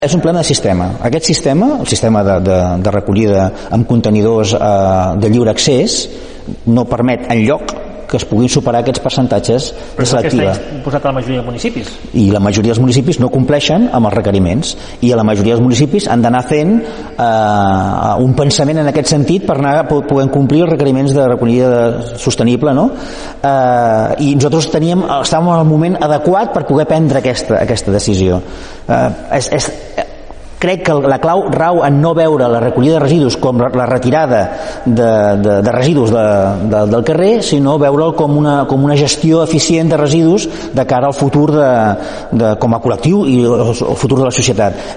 La darrera entrevista del programa Assumptes Interns de Ràdio Palafolls ha tingut com a convidat a l’alcalde de Palafolls, Francesc Alemany, amb qui es va repassar l’actualitat municipal després d’haver-ho fet amb la resta de representants polítics del ple.